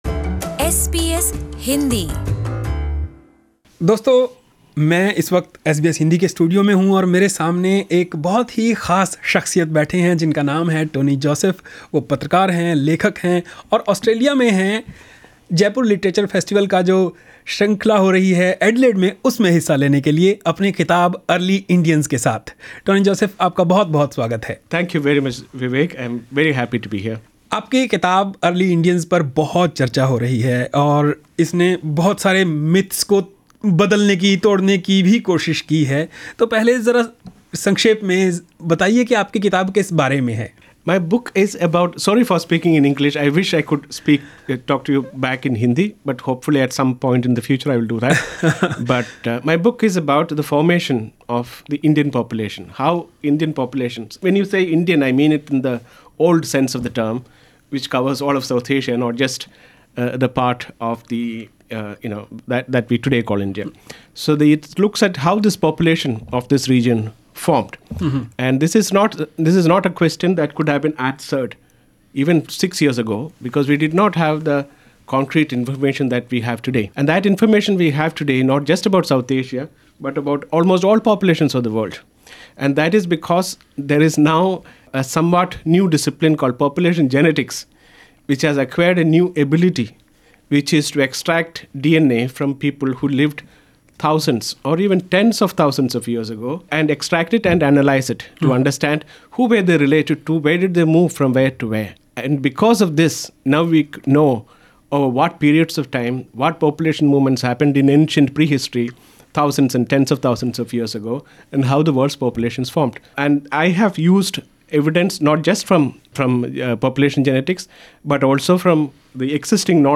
Speaking to SBS Hindi, Mr Joseph discussed different aspects of the Indian history.